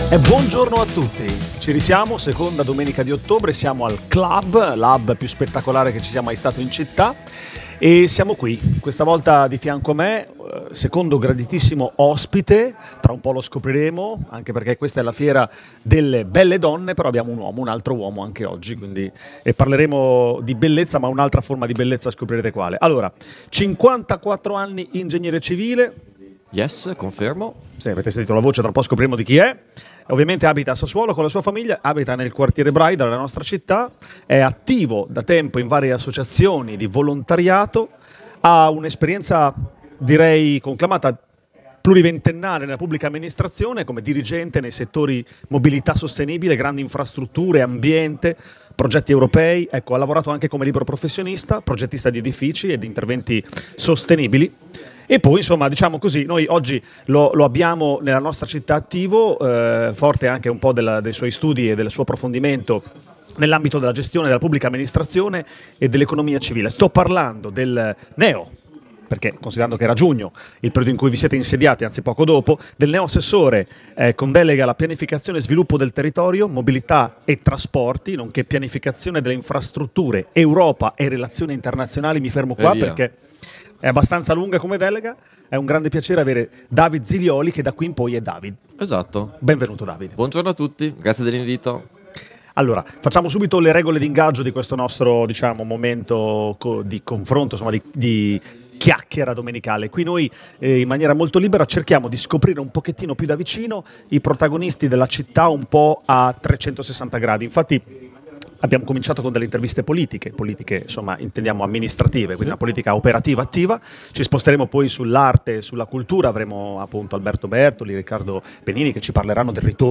Intervista
Le interviste di Linea Radio al Clhub di viale XX Settembre a Sassuolo